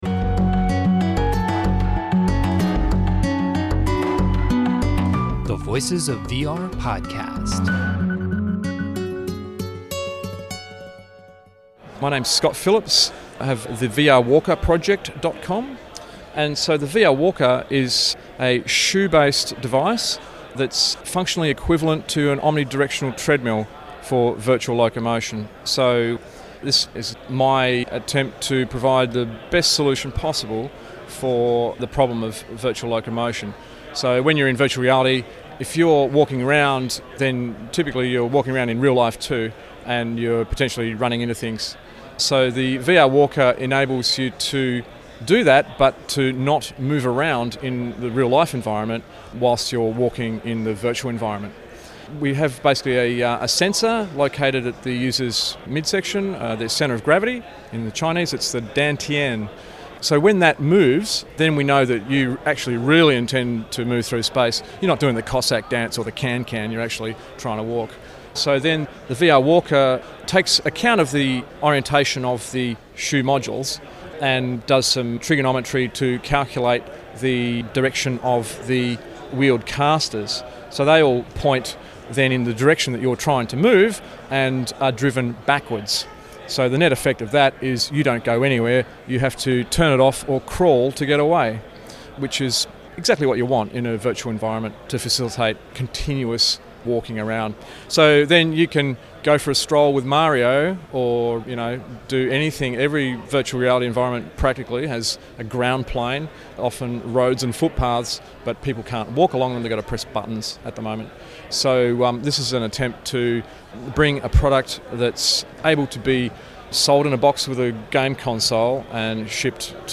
I decided to record the pitch in this interview, and it turned out to be one of the most interesting surprises of the conference that ranged from VR locomotion, early inspirations from one of the first famous VR experiences, and ended up at occult applications of the VR walker for psychological explorations into the human condition.